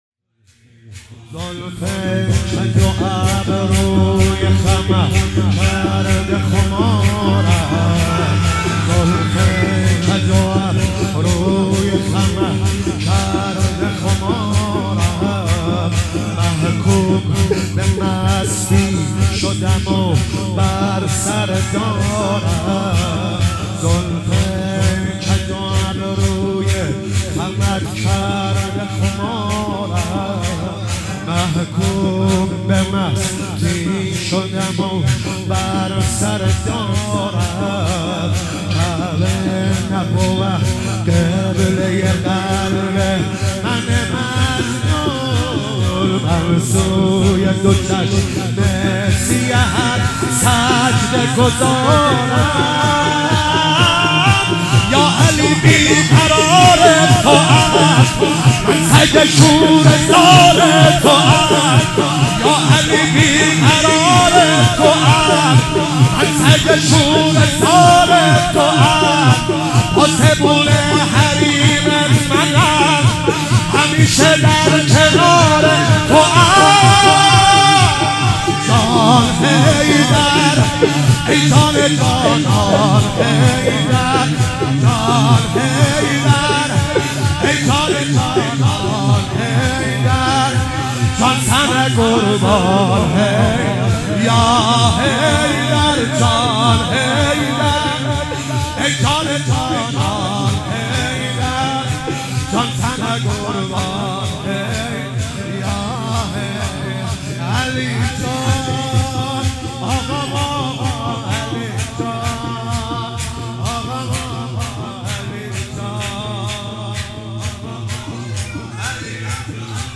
ماه رمضان